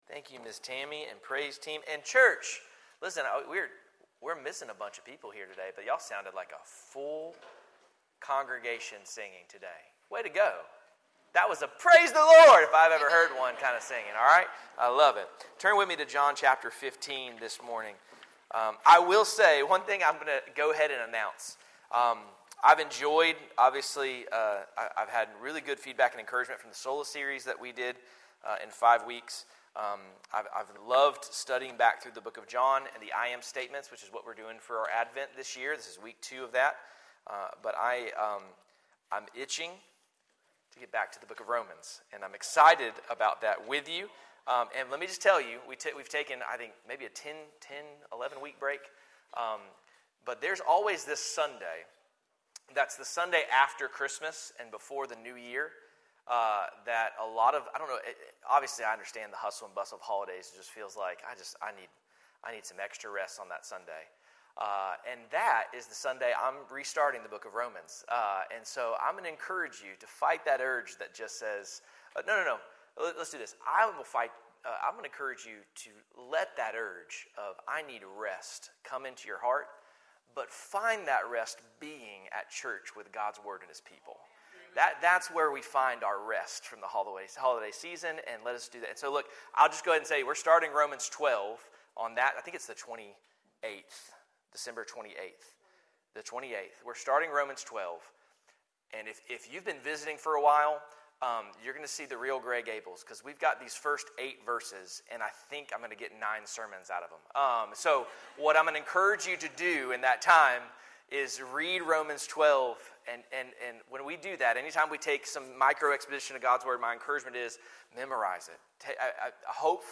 Sermons | First Baptist Church of Gray Gables